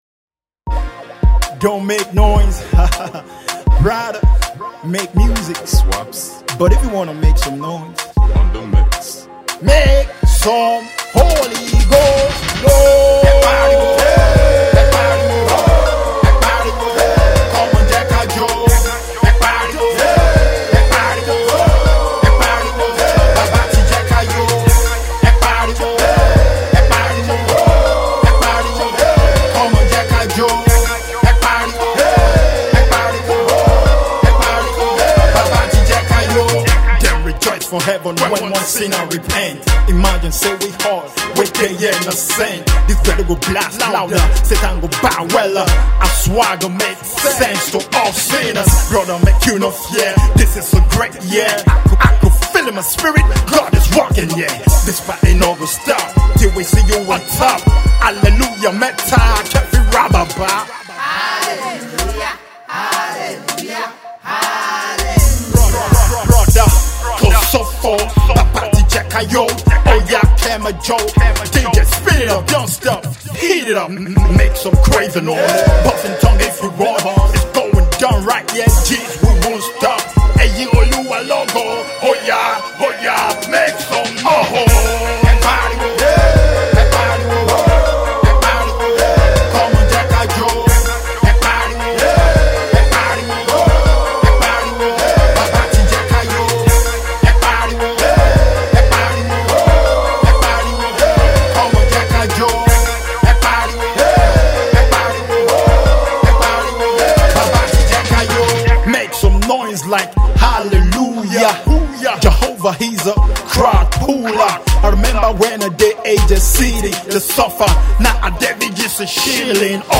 makes some praizy noise with his new single